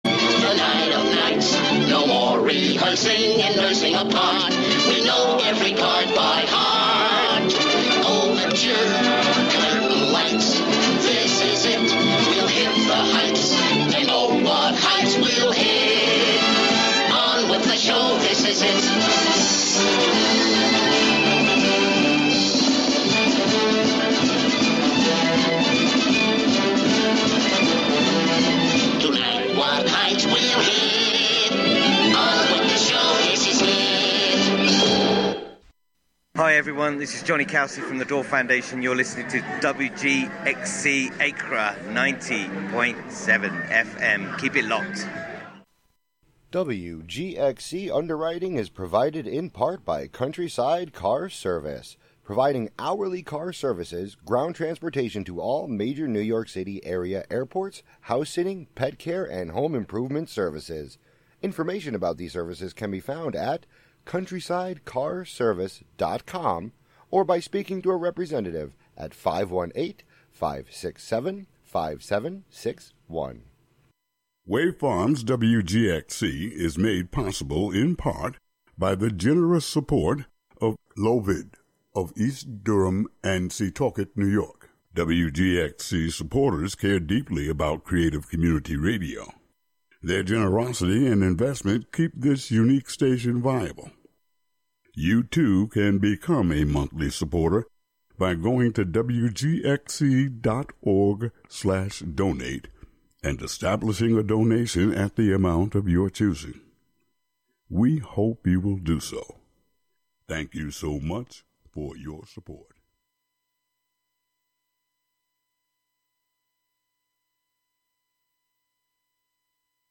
This music mix show